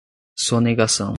Pronunciado como (IPA)
/so.ne.ɡaˈsɐ̃w̃/